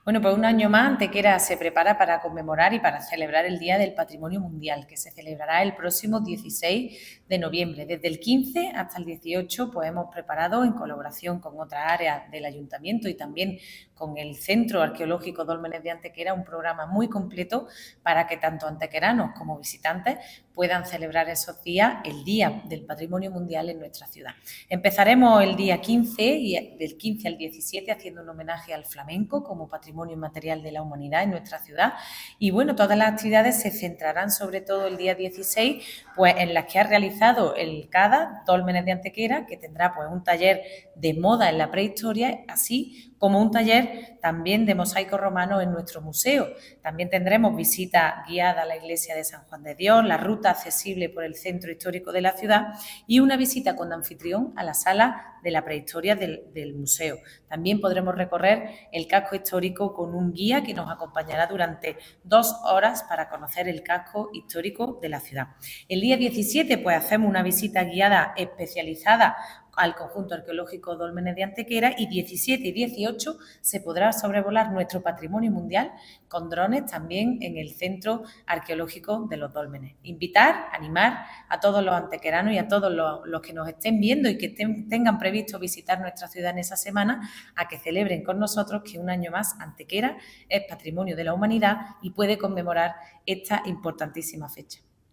La teniente de alcalde delegada de Turismo, Comercio y Patrimonio Mundial, Ana Cebrián, informa del programa de actividades que el Ayuntamiento de Antequera promueve para conmemorar en nuestra ciudad la celebración del Día Internacional del Patrimonio Mundial en torno al 16 de noviembre.
Cortes de voz